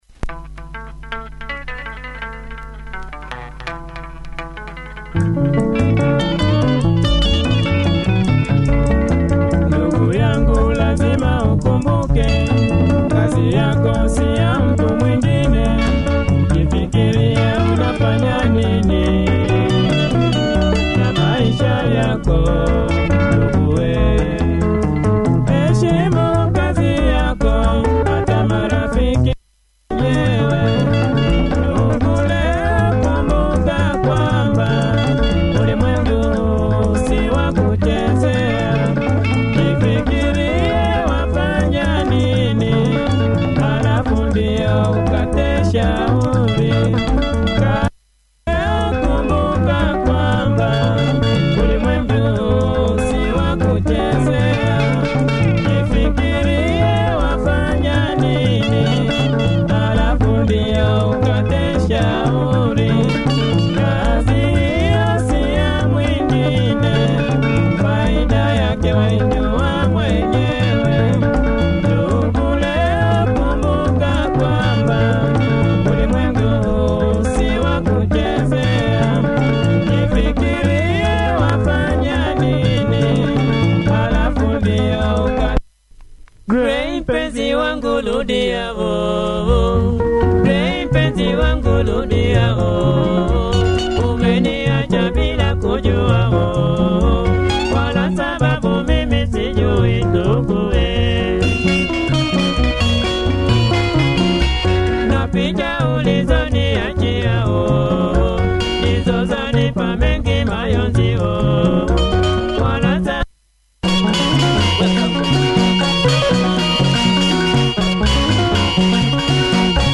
Clean copy, check audio! https